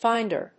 音節fínd・er 発音記号・読み方
/ˈfaɪndɝ(米国英語), ˈfaɪndɜ:(英国英語)/